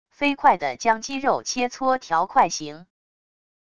飞快的将鸡肉切磋条块行wav音频